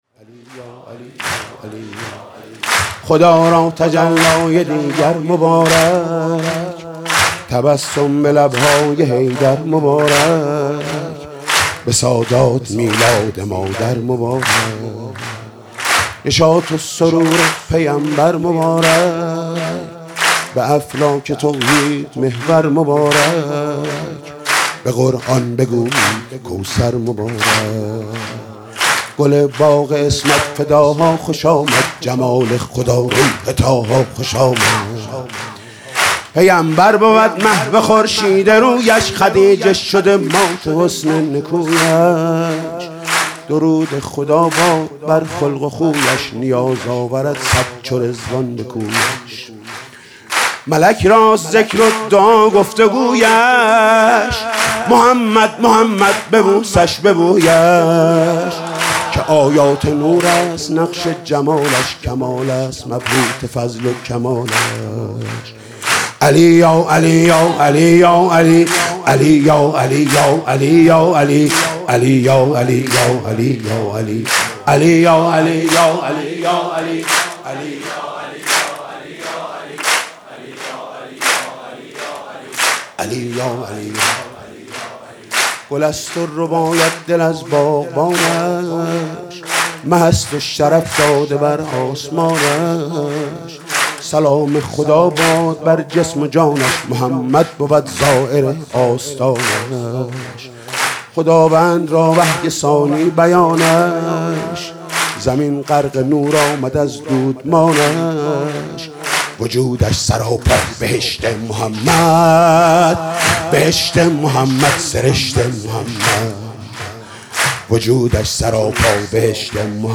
سرود: خدا را تجلای دیگر مبارک